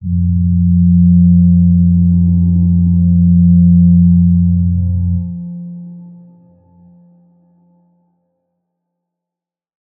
G_Crystal-F3-mf.wav